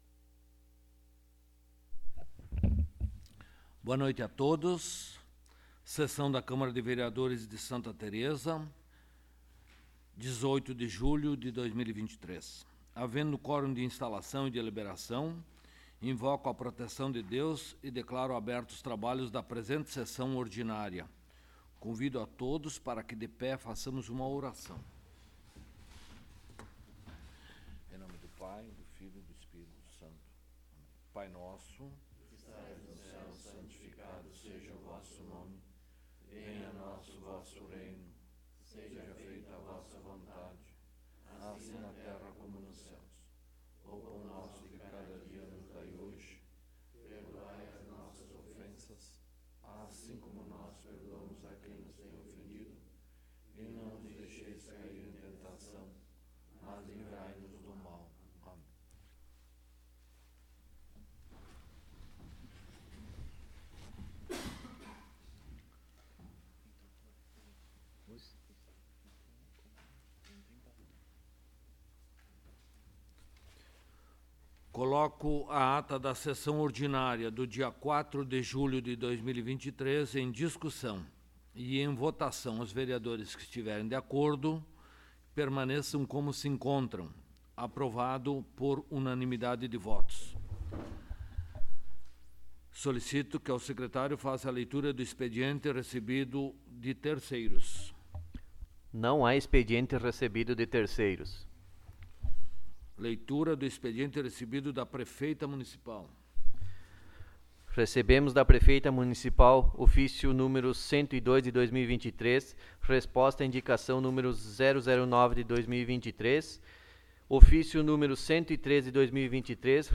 12º Sessão Ordinária de 2023
Áudio da Sessão